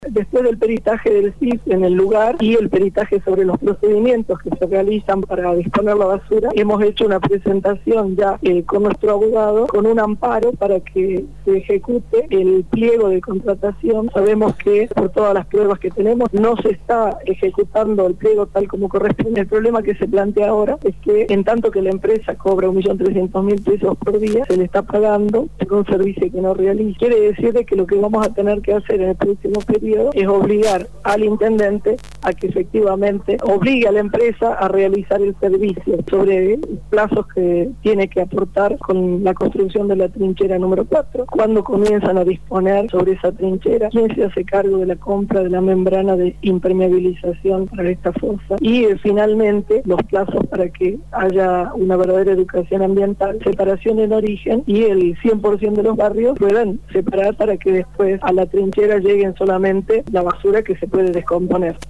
La concejal del Partido Obrero, Cristina Foffani, se refirió a cómo sigue la causa del vertedero San Javier y cuáles serán las acciones que piensan llevar adelante.